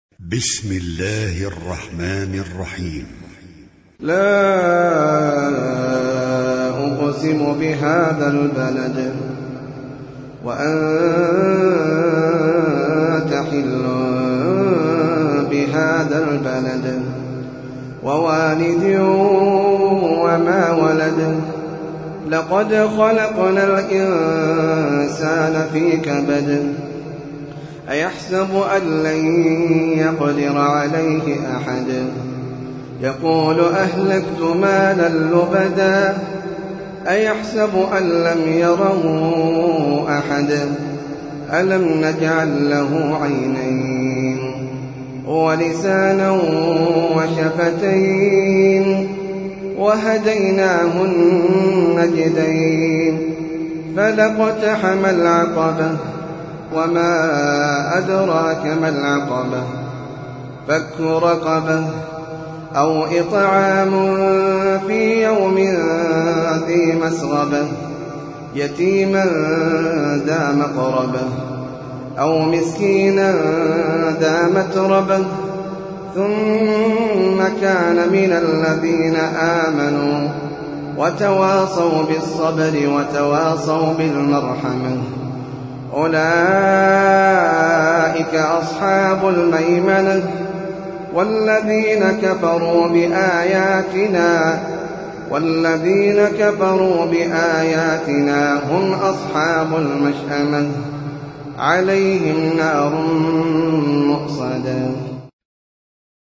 سورة البلد - المصحف المرتل
جودة عالية